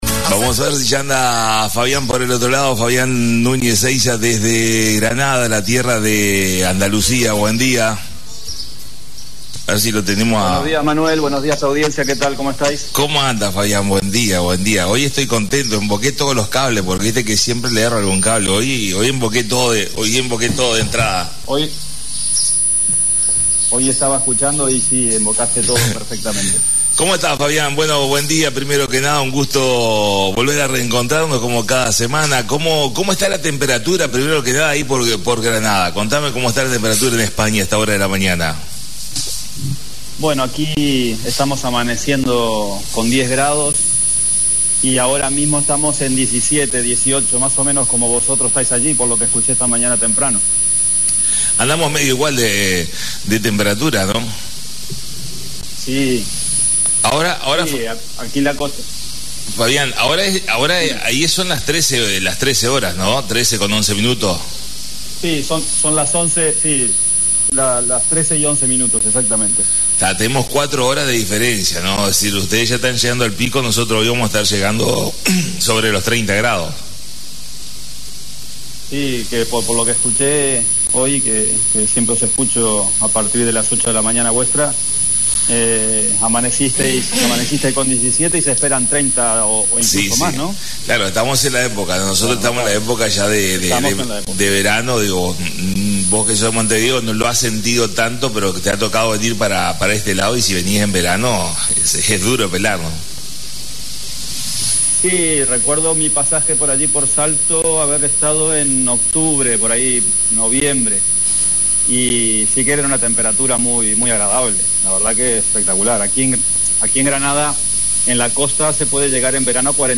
Como cada martes a las 9 nos comunicamos en vivo